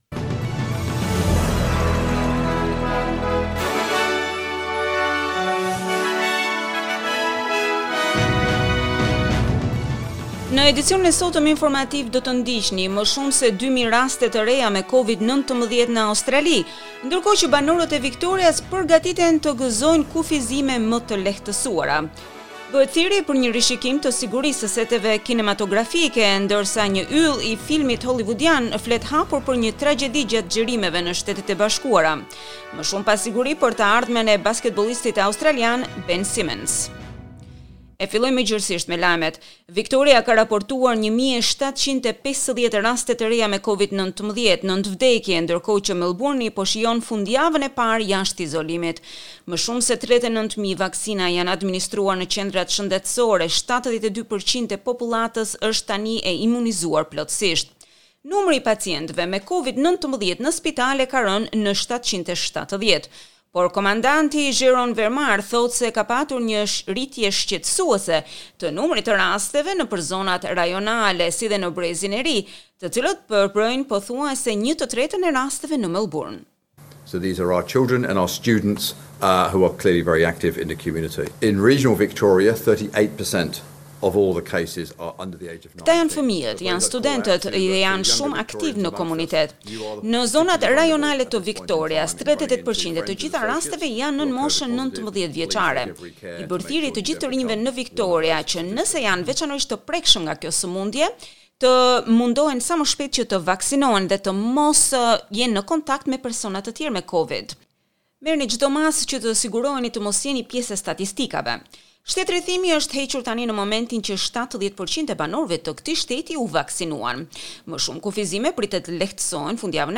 SBS News Bulletin in Albanian - 23 October 2021